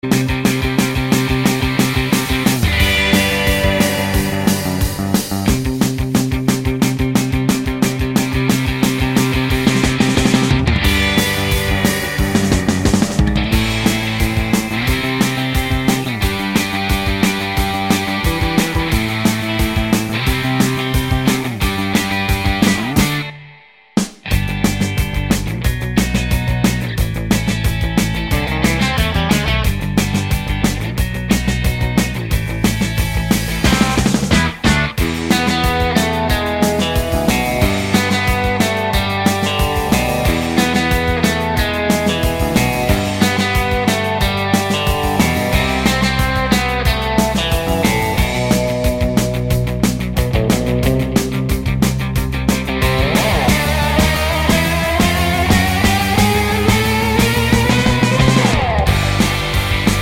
no Backing Vocals Punk 3:55 Buy £1.50